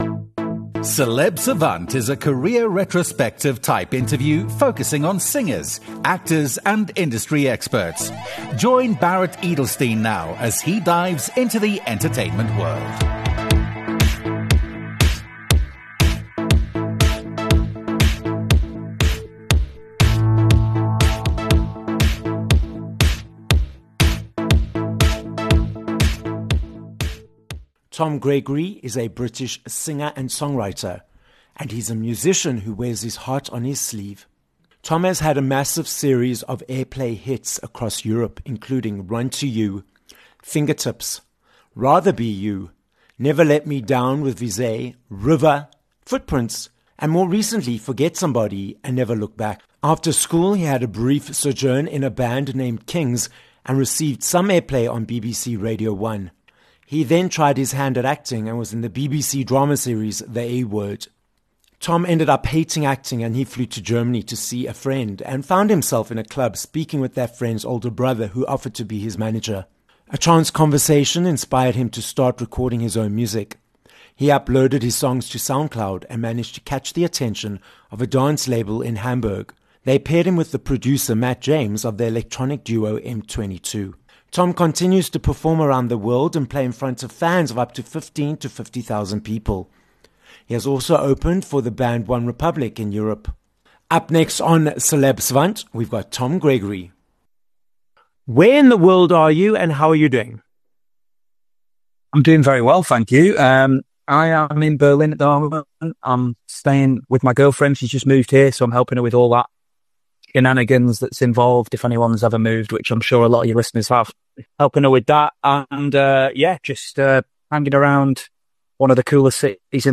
Tom Gregory - a British singer and songwriter who has 1.8 million monthly listeners on Spotify - joins us on this episode of Celeb Savant. Tom explains that after quitting university after one week because he hated it, forced him to realise that he wanted to pursue music professionally. Plus - what is surprising about becoming a professional musician, and how he stays motivated by expanding the goalposts.